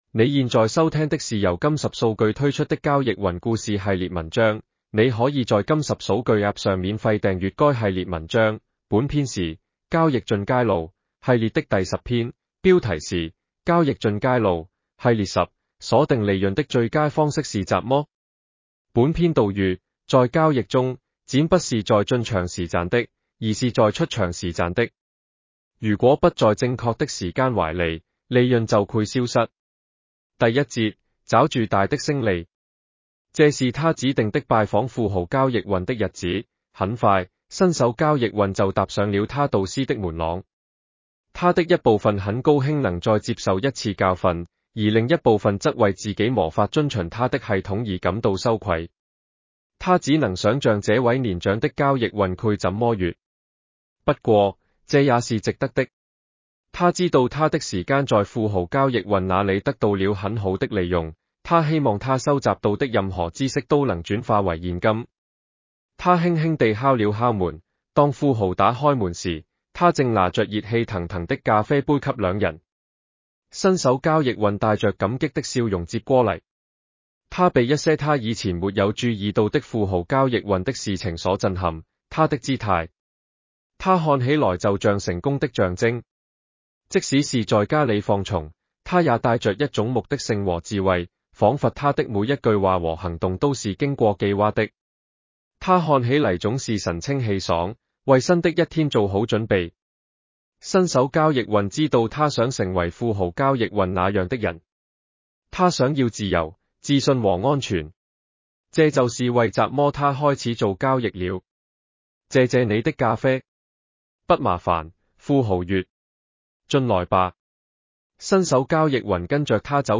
男生普通话版 下载mp3 您也可以 下载mp3 在开车或散步时收听 粤语版 下载mp3 西南方言版 下载mp3 东北话版 下载mp3 上海话版 下载mp3 本文内容节选自交易书籍《New Trader Rich Trader》，加入一名新手交易员的旅程，学习在交易中获得成功的方法。